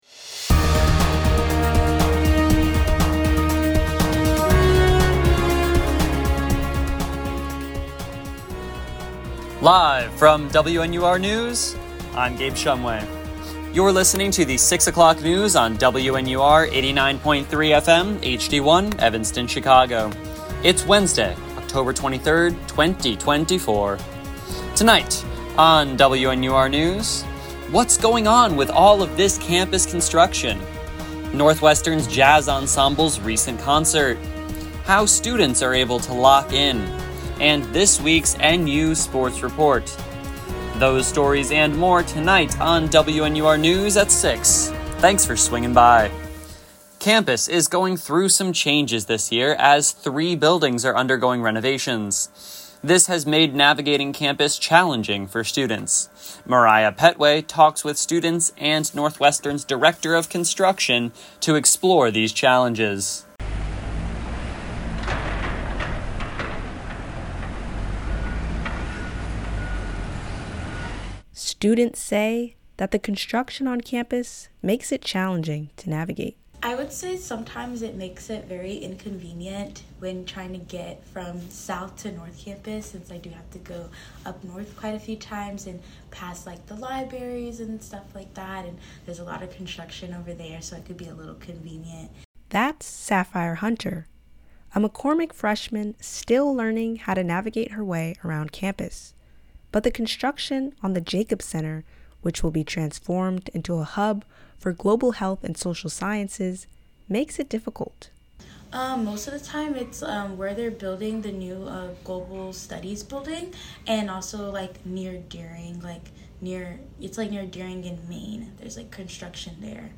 October 23, 2024: Campus construction, Northwestern Jazz Ensemble Concert, Lock in music, and the NU Sports Report. WNUR News broadcasts live at 6 pm CST on Mondays, Wednesdays, and Fridays on WNUR 89.3 FM.